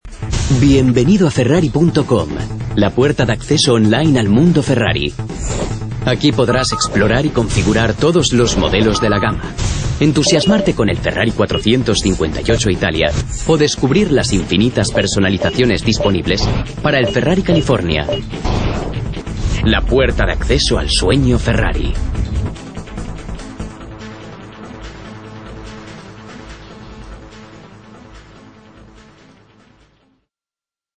Speaker madrelingua spagnolo dalla voce calda e versatile.
Sprechprobe: Industrie (Muttersprache):
Native Spanish voice-artist with a warm and versatile voice. Specialized in accents and professional dubbing.